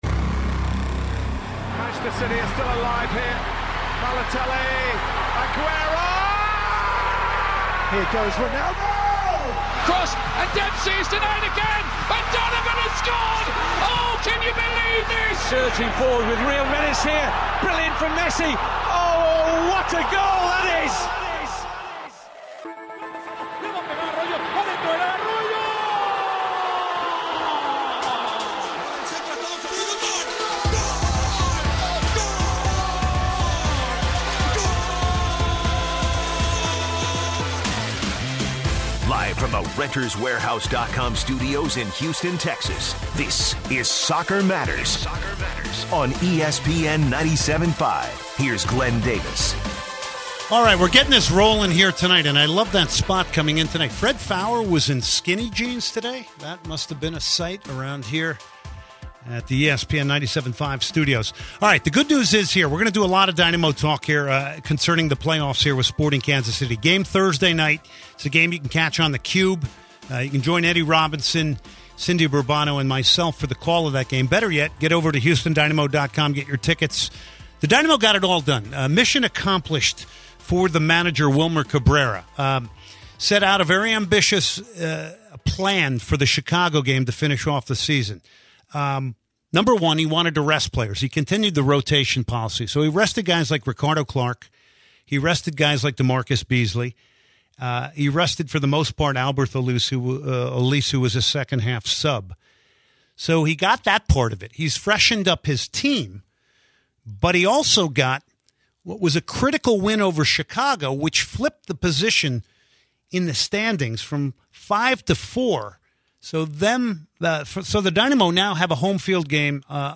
roundtable discussion
in studio guests